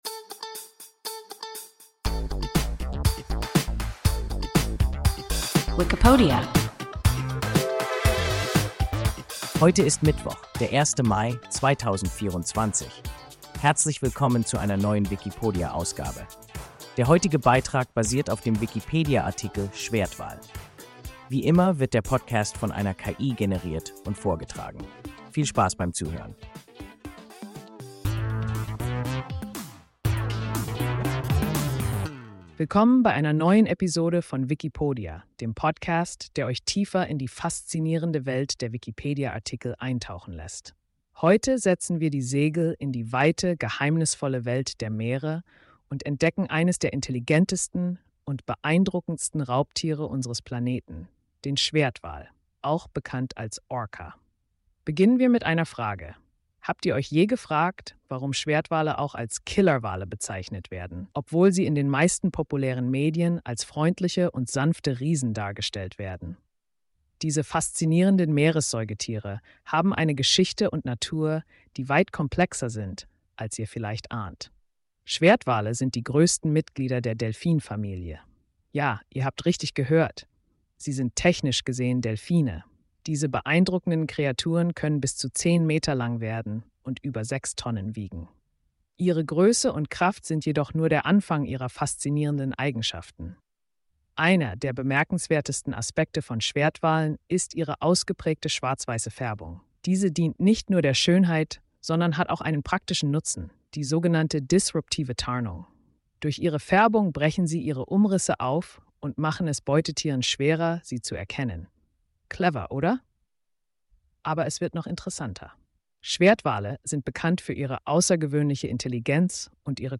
Schwertwal – WIKIPODIA – ein KI Podcast